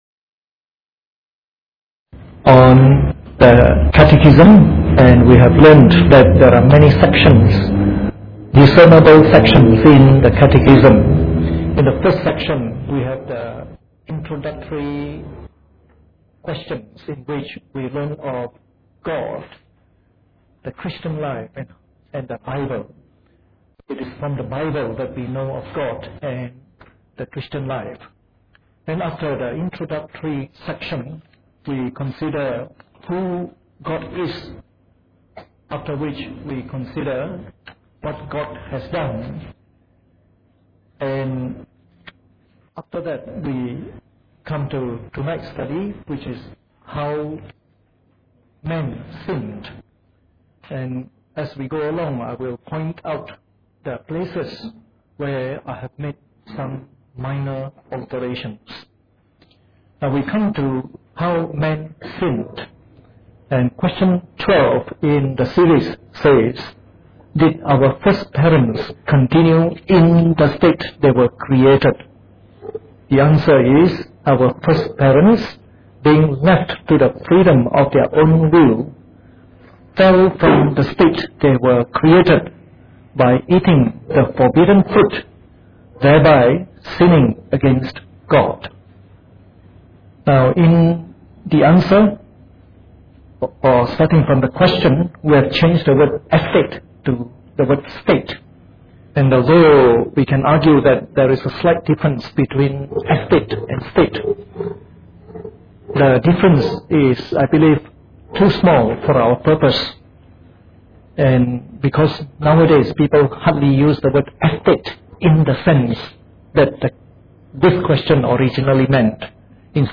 Preached on the 2nd of March 2011 during the Bible Study from our current series on the Shorter Catechism.